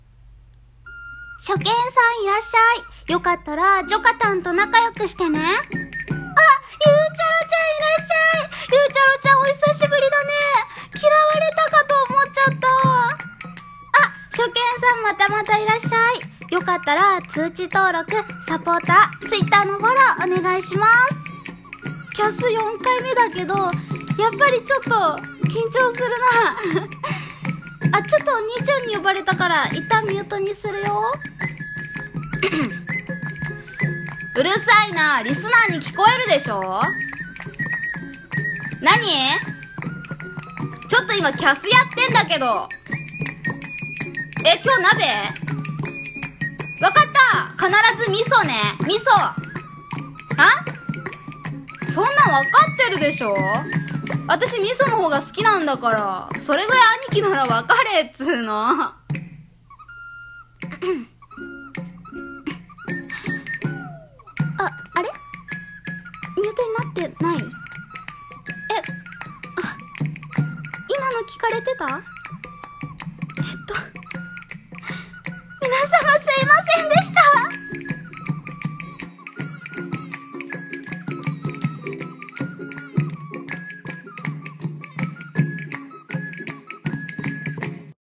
【声劇】萌え声が地声バレる【1人声劇】